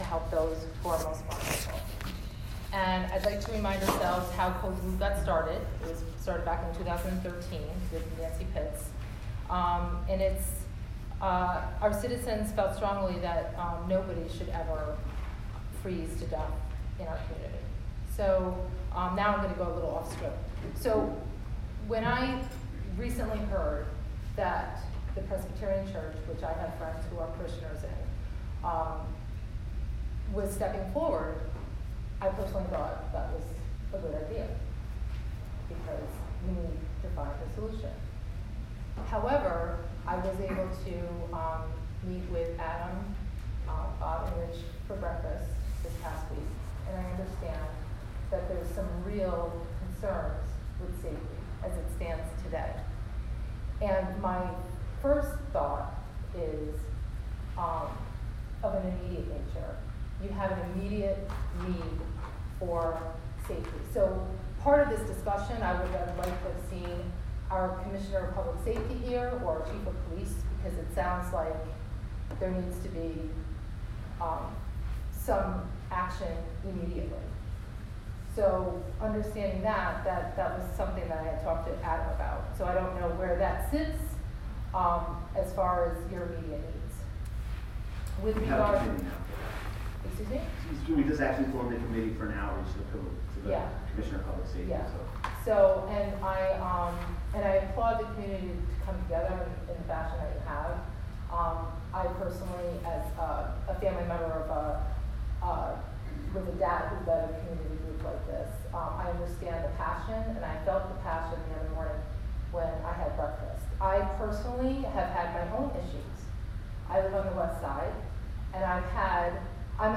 Here is a recording of that meeting with presentations by each of the invited guests followed by Q and A.